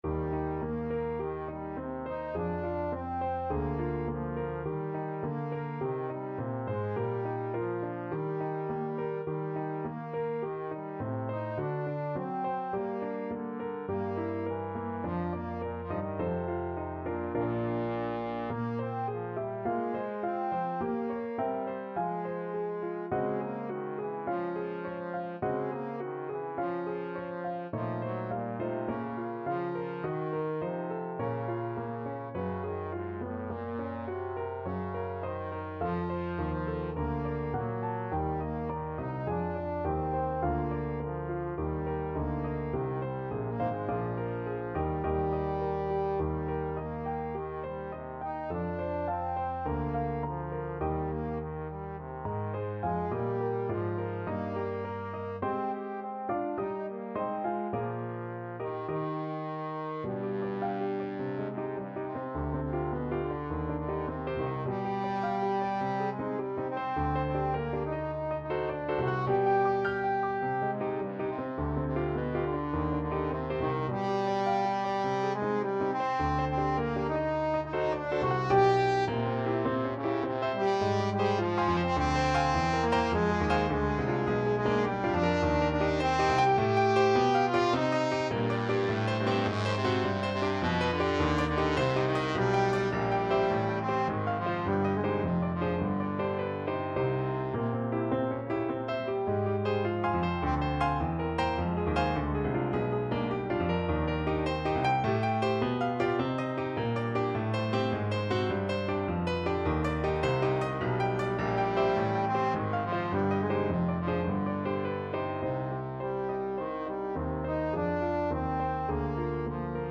Classical Schumann, Robert Three Romances for Oboe, Op.94. No.2 Trombone version
Eb major (Sounding Pitch) (View more Eb major Music for Trombone )
Einfach, innig =104
Classical (View more Classical Trombone Music)
schumann_op94_romance_2_TBNE.mp3